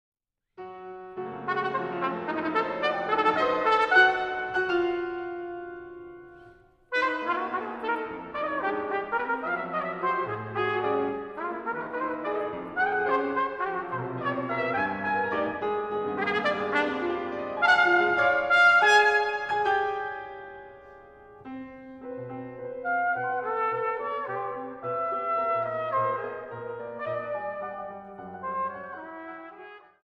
Piano
for trumpet and piano